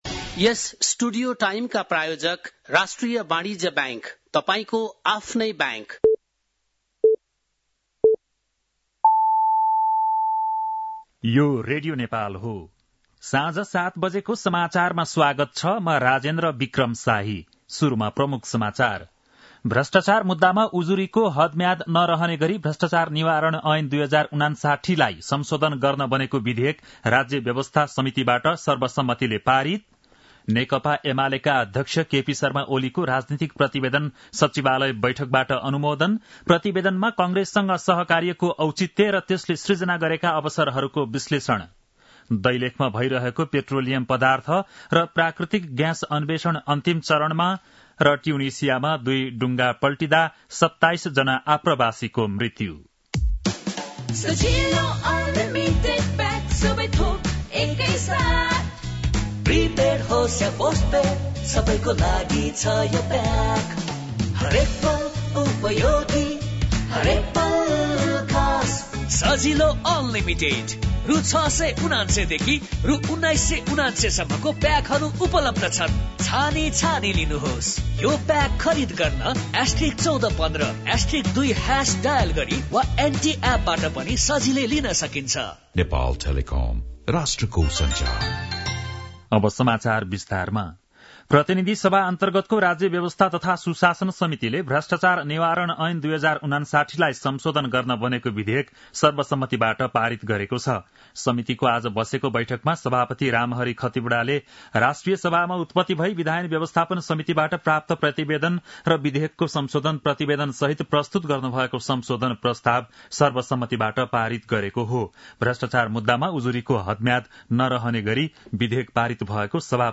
बेलुकी ७ बजेको नेपाली समाचार : १९ पुष , २०८१
7-pm-nepali-news-9-18.mp3